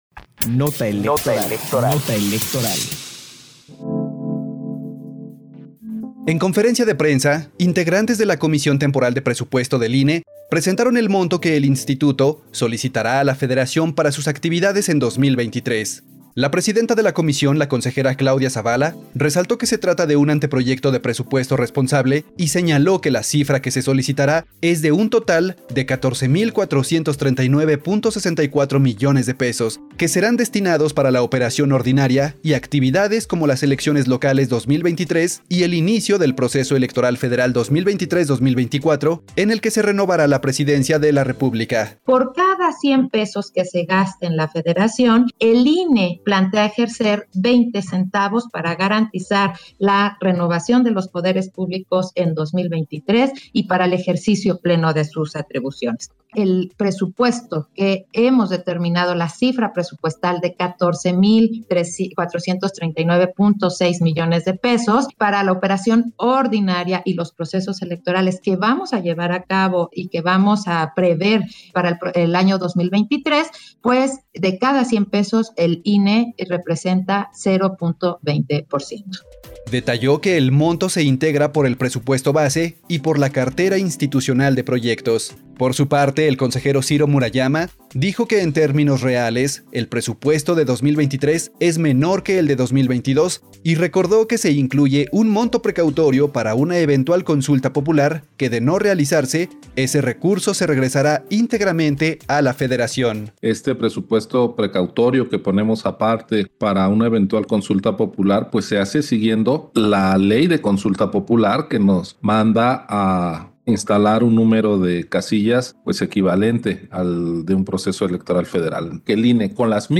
PD_1308_NOTA ELEC_CONFERENCIA DE PRENSA COMISION PRESUPUESTO 15 agosto 2022_CE - Central Electoral